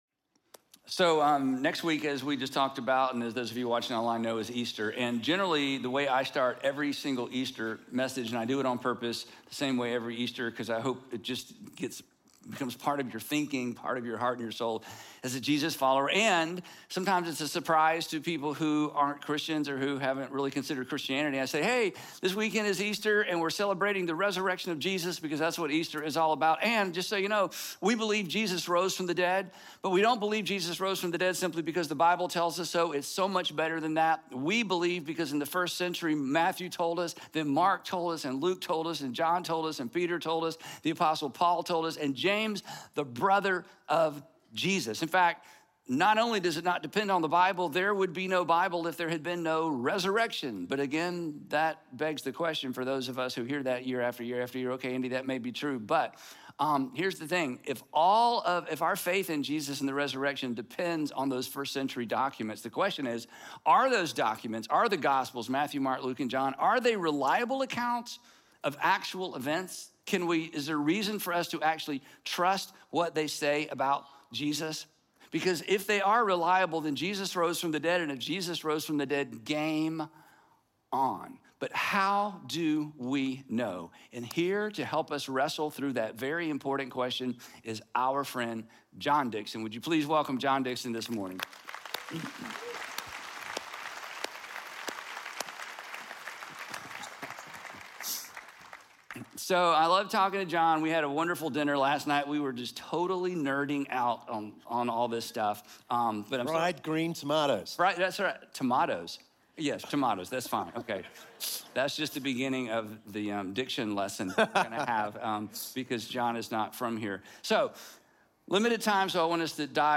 The gap between skepticism and faith starts to close when Christianity is viewed through the lens of history. In this conversation with Andy Stanley and John Di…